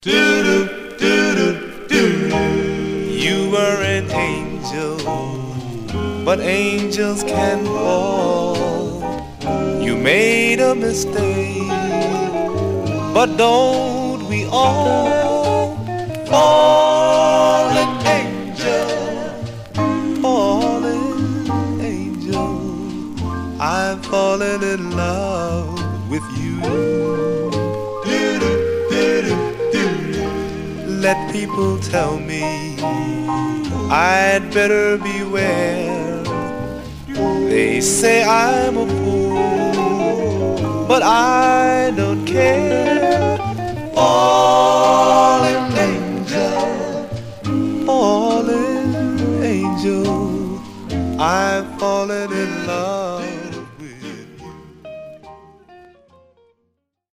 Stereo/mono Mono
Male Black Group